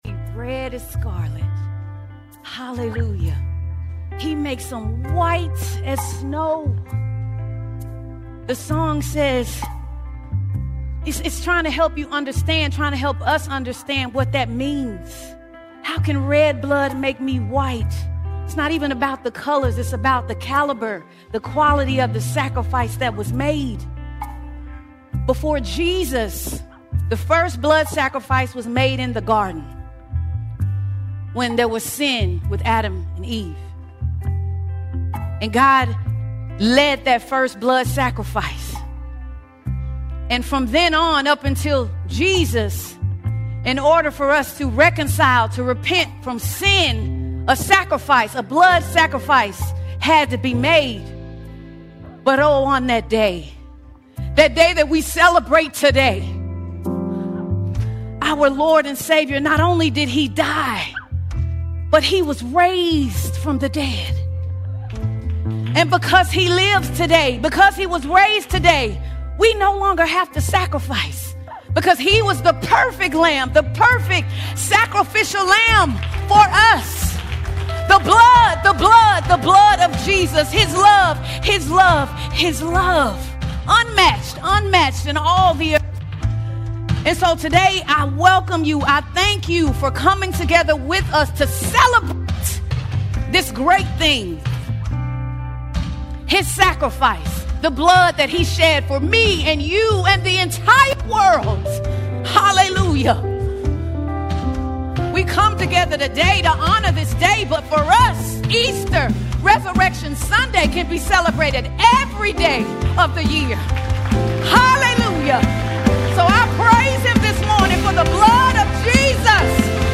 5 April 2026 Series: Sunday Sermons All Sermons The Great Exchange The Great Exchange The Great Exchange reveals that Easter is more than Christ’s death—it is the divine exchange offered to all humanity.